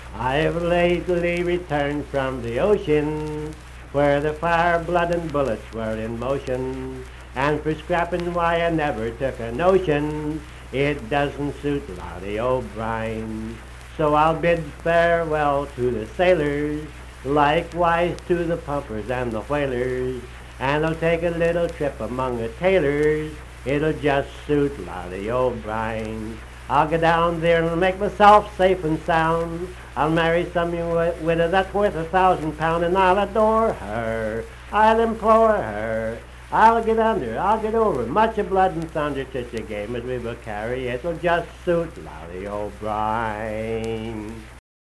Unaccompanied vocal music
Verse-refrain 1(12). Performed in Hundred, Wetzel County, WV.
Ethnic Songs, Bawdy Songs
Voice (sung)